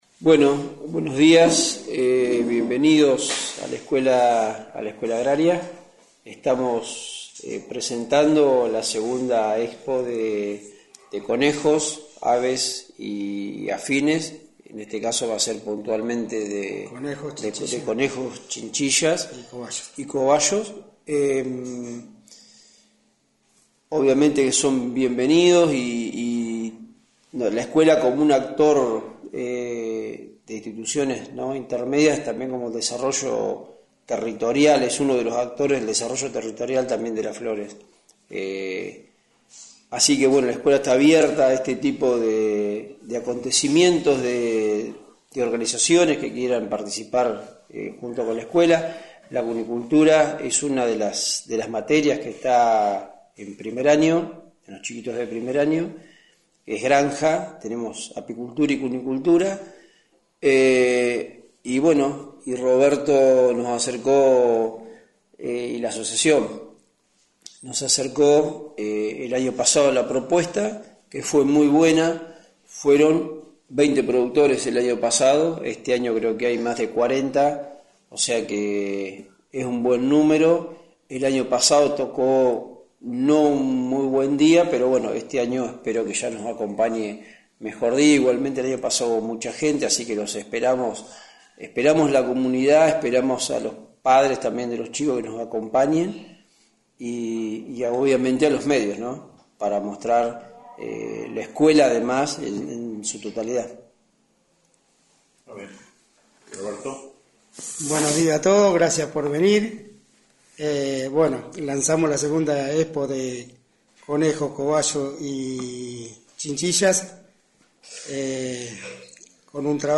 Conferencia-Conejos-2024.mp3